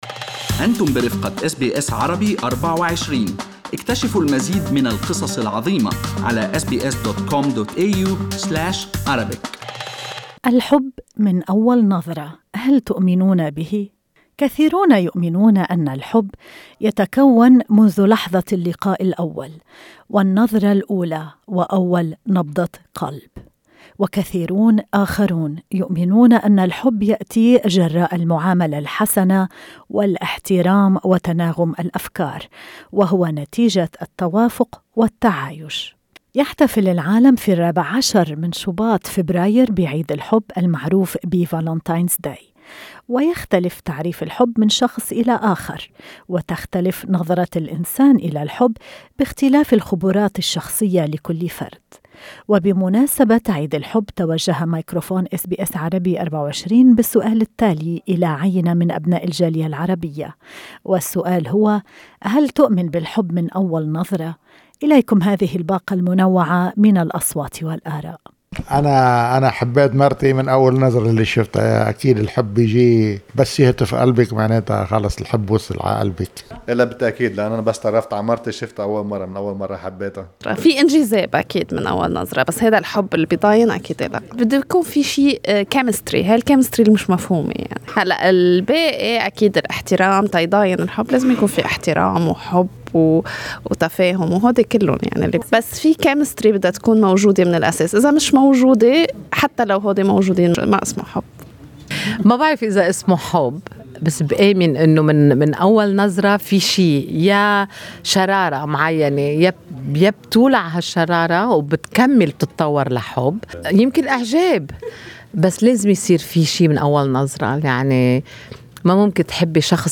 وبمناسبة "عيد الحب"، وكل يوم هو فرصة جديدة لعيش المحبة والإختفاء بالحب، توجه ميكروفون اس بي اس عربي 24 الى عينة من أبناء الجالية العربية في سيدني بالسؤال التالي: هل تؤمنون بالحب من النظرة الأولى؟